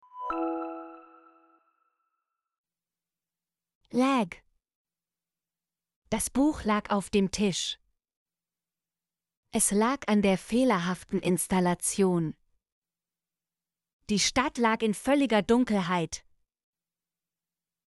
lag - Example Sentences & Pronunciation, German Frequency List